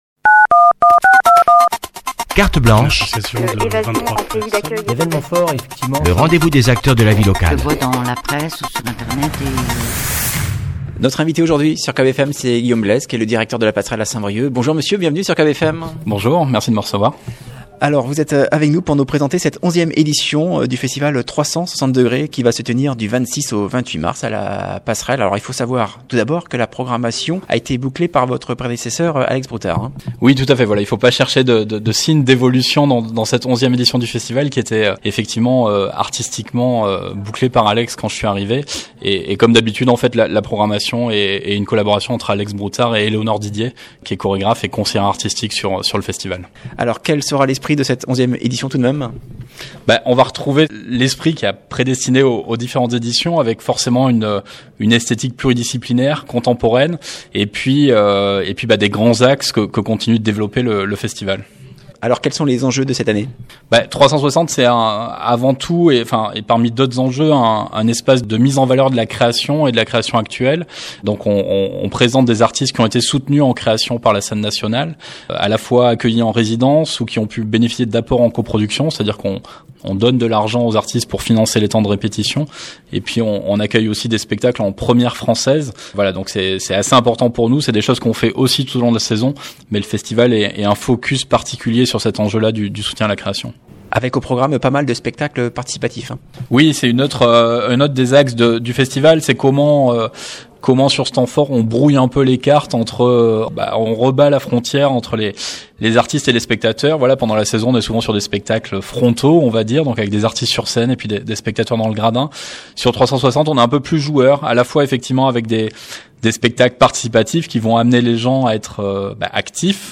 Invité de la rédaction ce lundi